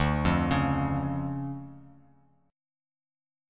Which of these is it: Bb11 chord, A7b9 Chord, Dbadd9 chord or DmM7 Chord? DmM7 Chord